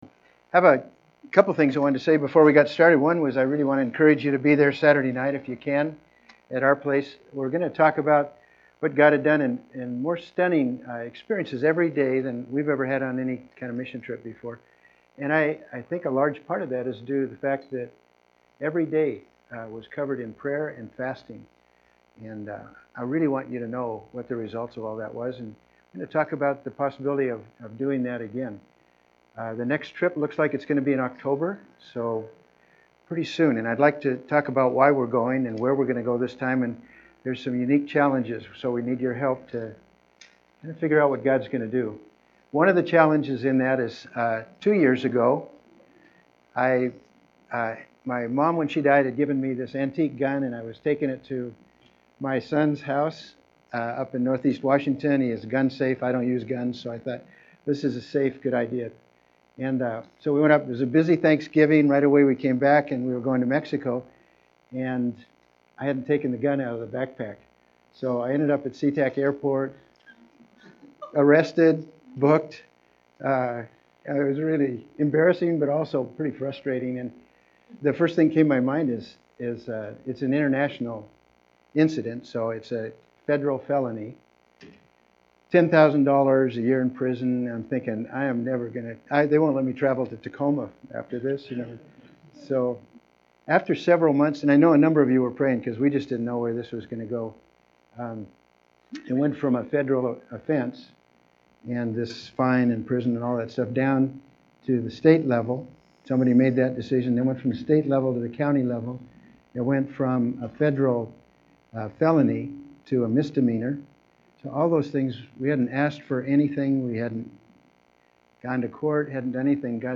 Download Download From this series Disciple Part 2 Disciple Guest Speaker September 15, 2019 Current Sermon Disciple or something else Disciple Guest Speaker September 8, 2019 View all Sermons in Series